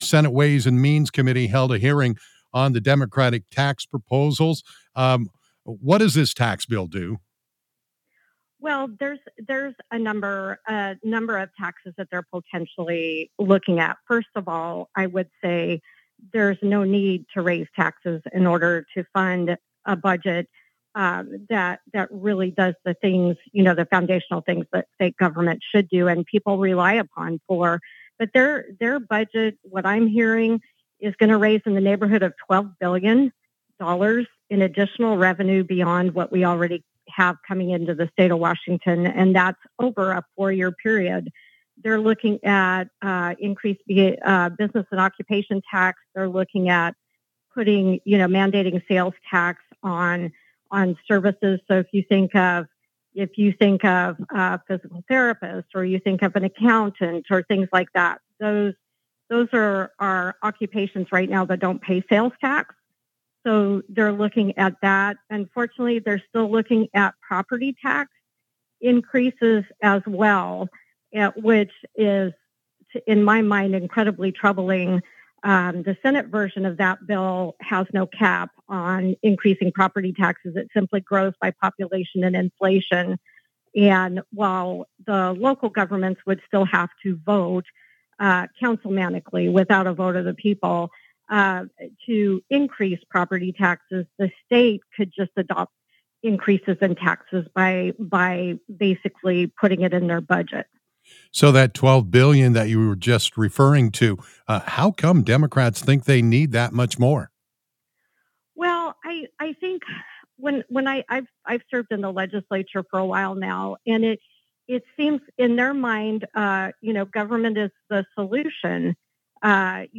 Senator Shelly Short discussed Democratic tax proposals, wildfire funding, and the governor’s budget stance on KOZI radio.